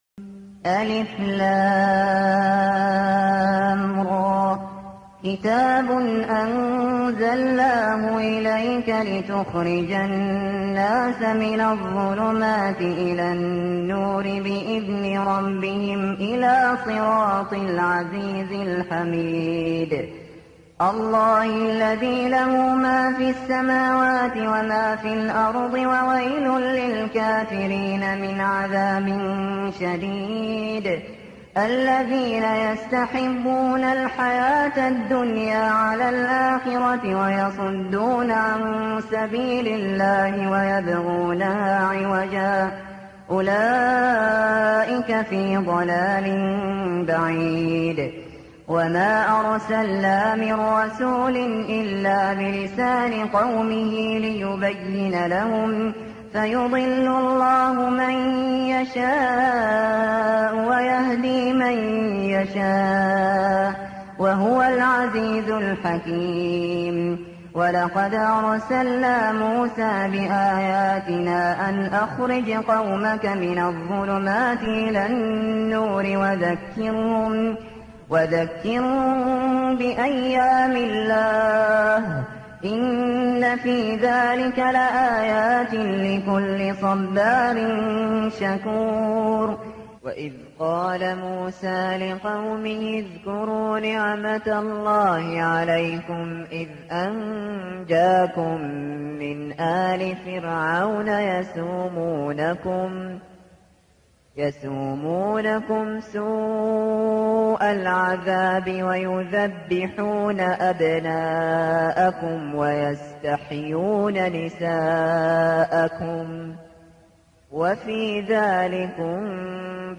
جديد - الشيخ ابو بكر الشاطري : سورة إبراهيم - قراءه قديمه وخاشعه من عام 1412هـ / 1992م